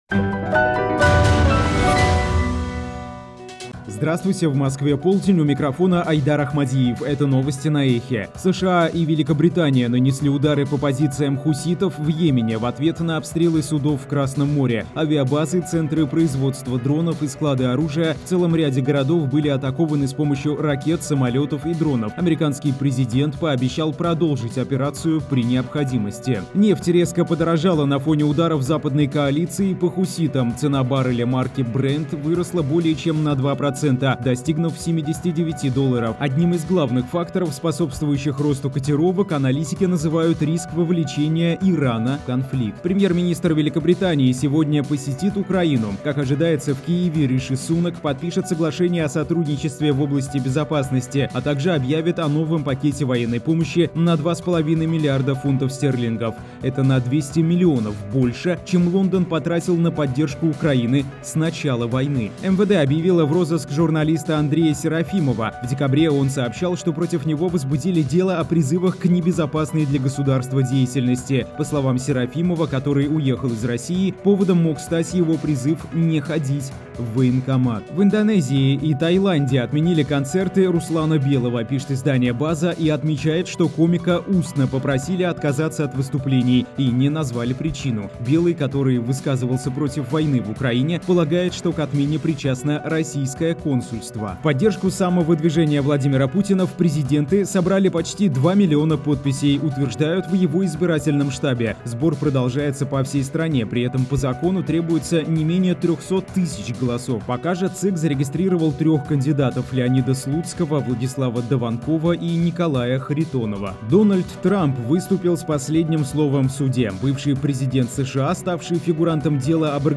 Слушайте свежий выпуск новостей «Эха»
новости 12:00